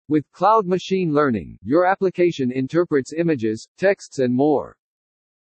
All are professional business voices.
en-US-Standard-B.mp3